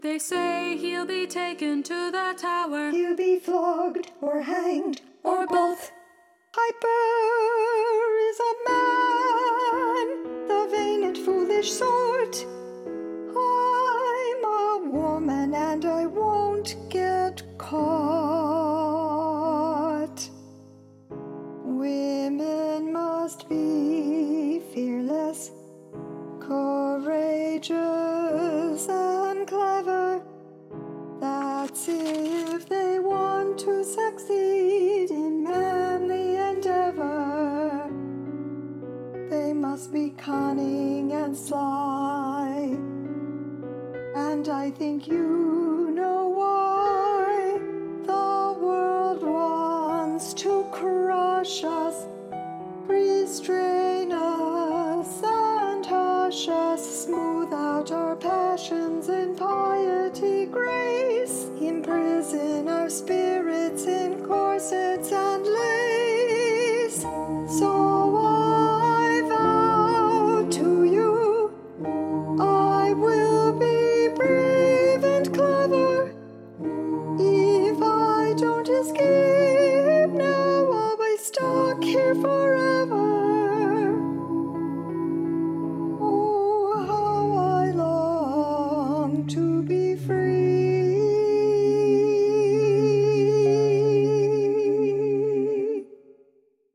Hear the passage with Mary and chorus singing
CC_MaryAudition-AllVoices-v2.mp3